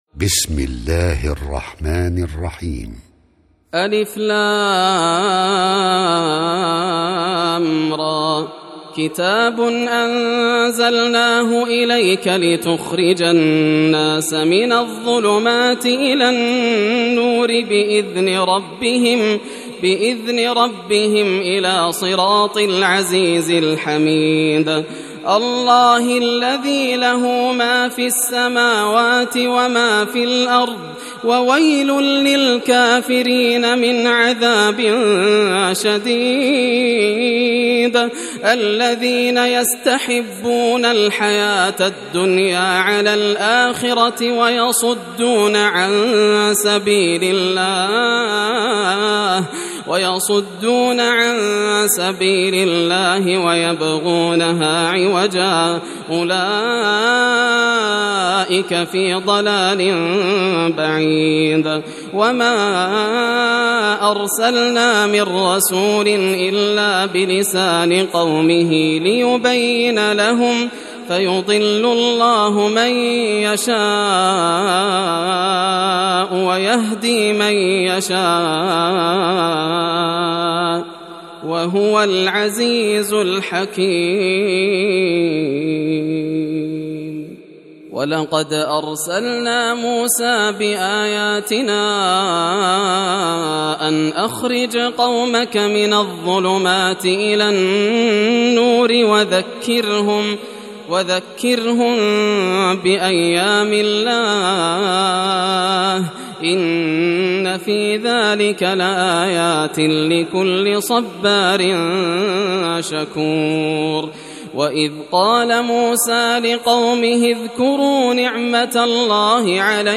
سورة إبراهيم > المصحف المرتل للشيخ ياسر الدوسري > المصحف - تلاوات الحرمين